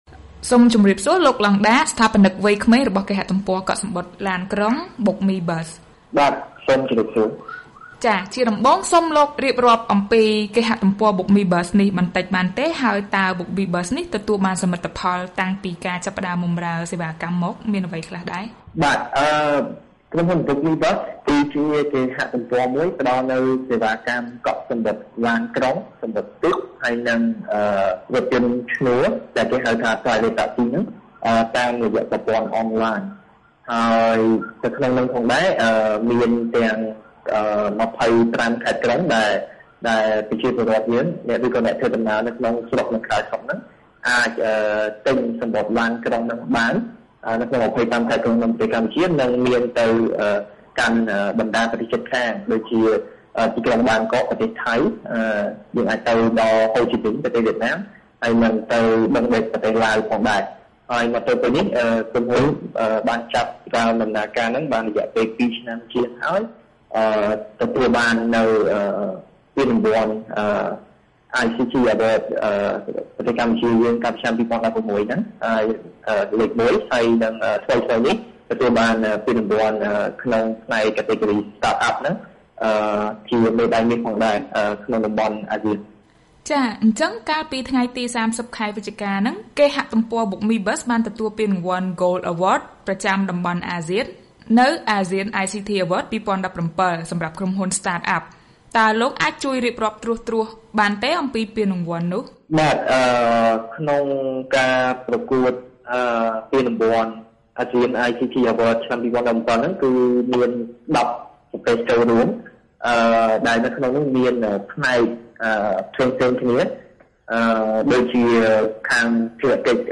បទសម្ភាសន៍ VOA៖ ជ័យលាភីមេដាយមាសចែករំលែកបទពិសោធលក់សំបុត្រអនឡាញ